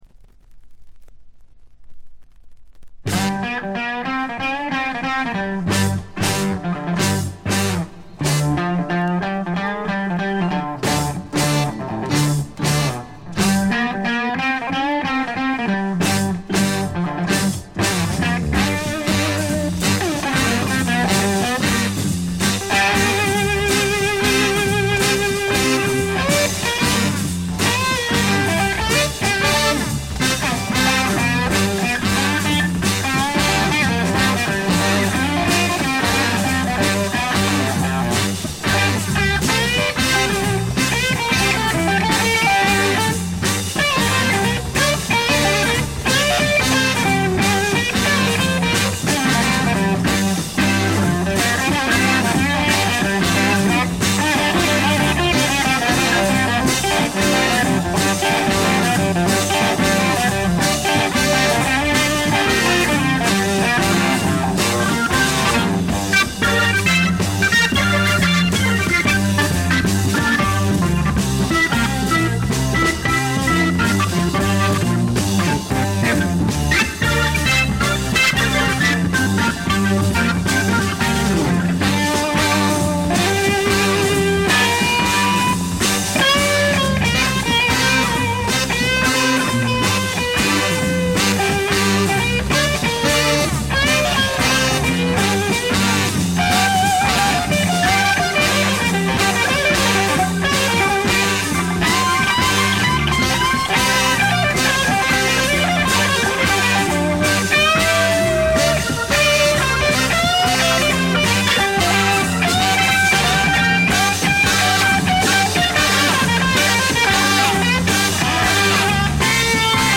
静音部（曲間しかないけど）で微細なチリプチが聴かれる程度でほとんどノイズ感無し。
モノラル・プレス。
ちょいと音量を上げれば暴風が吹き荒れるような感じ。
試聴曲は現品からの取り込み音源です。
lead vocals, piano, Hammond B3 organ, harmonica
bass guitar
drums